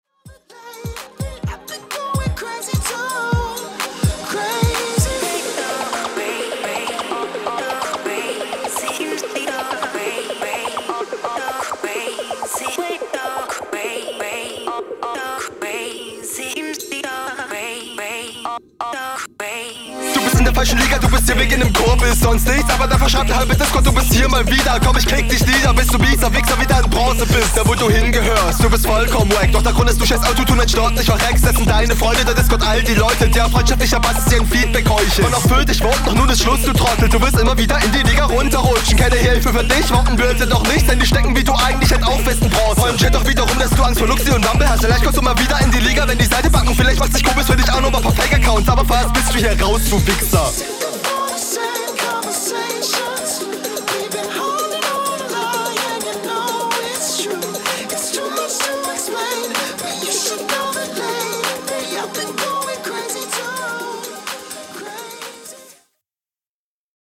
Beat übergeil, Inhaltlich etwas zu viel auf dem gleichen Thema ohne verschieden Herangehensweisen zu bringen, …